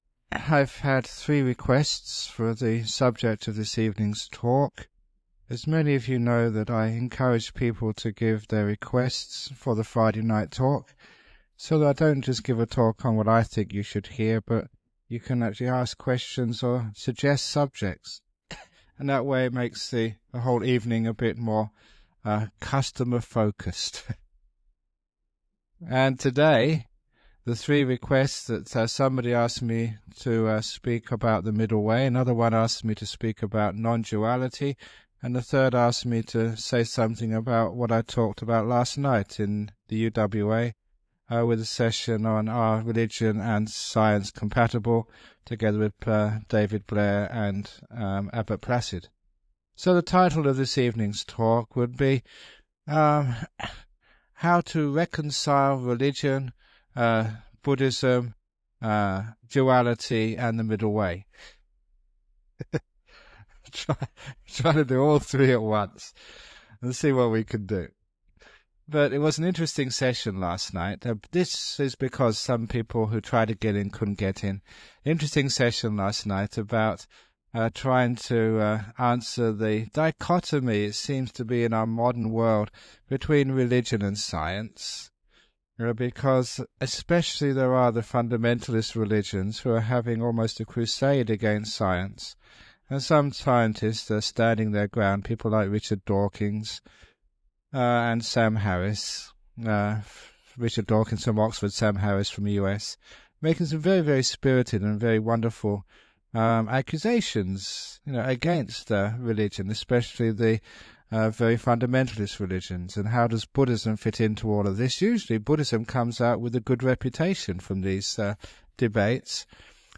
— This dhamma talk was originally recorded using a low quality MP3 to save on file size on 31st December 2004. It has now been remastered and published by the Everyday Dhamma Network, and will be of interest to his many fans.